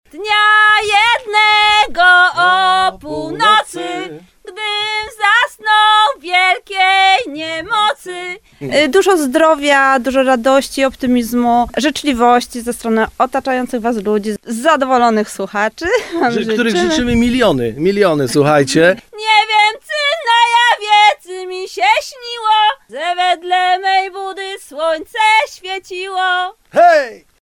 Przedstawiciele zespołu osobiście gościli w naszej redakcji, aby dzielić się radością o zbliżającej się Dobrej Nowinie i przekazać symboliczny stroik.